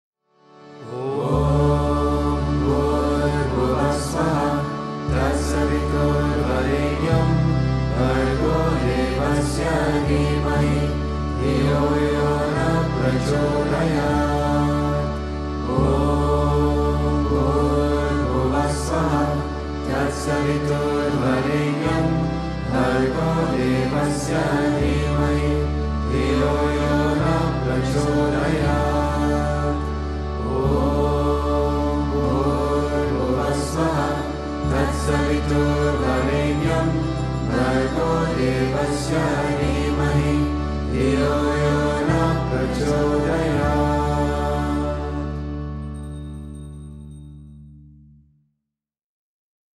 Gayatri-Mantra.m4a